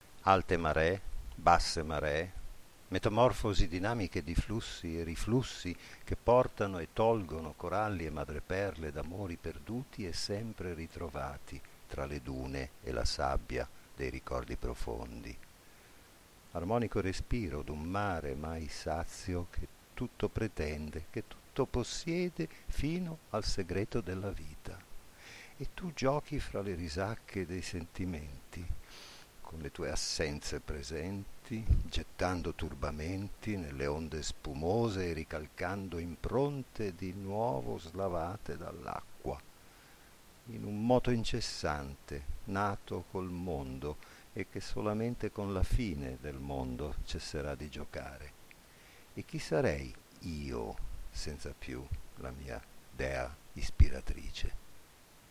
Ascolta la poesia letta dall'autore